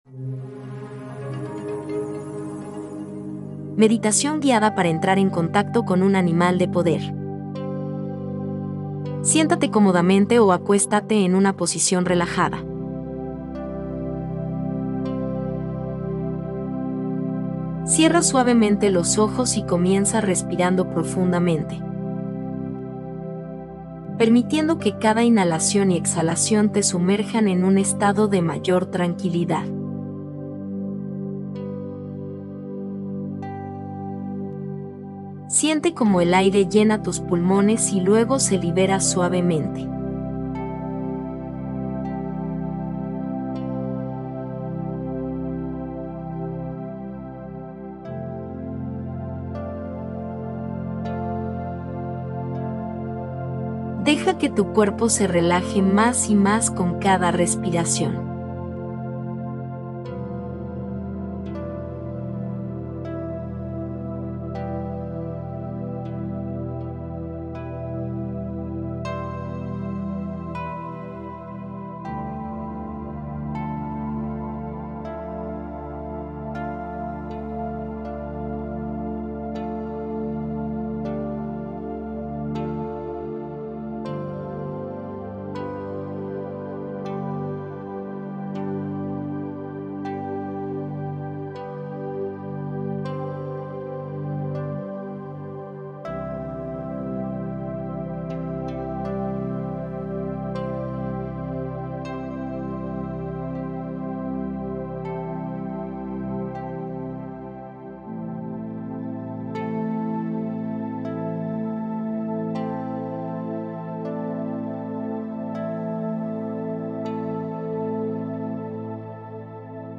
MEDITACIÓN GUIADA